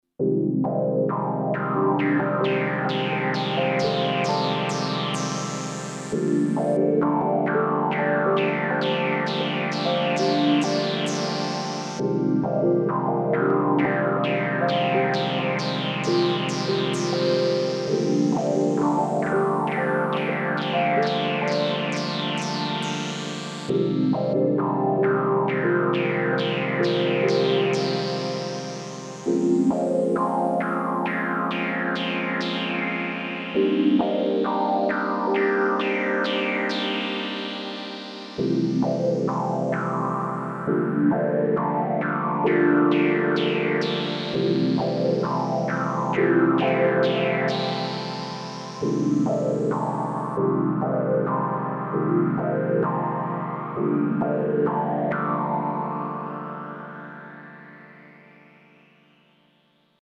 Waldorf M bank 7 patch 31 “Epsilon Eridani MSt”
type of patch I can just noodle on for hours